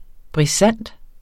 Udtale [ bʁiˈsanˀd ]